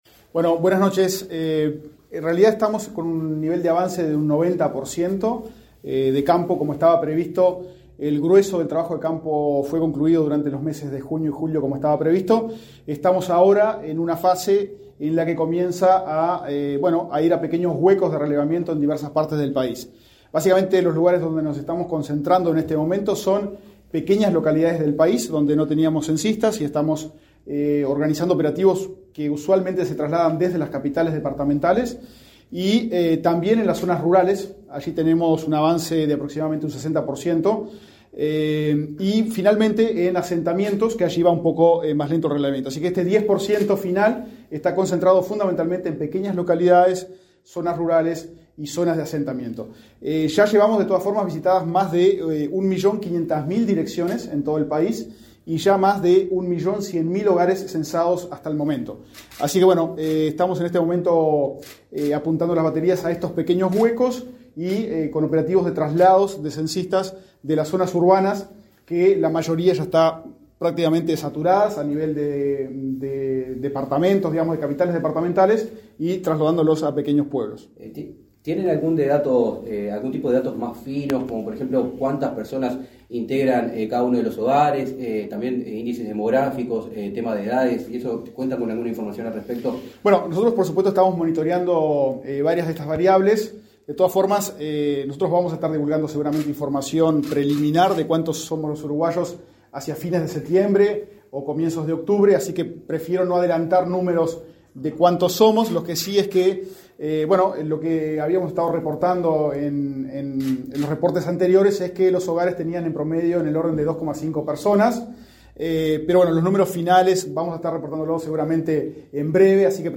Declaraciones a la prensa del director del INE, Diego Aboal
En la oportunidad, el director del organismo, Diego Aboal, respondió las preguntas de la prensa.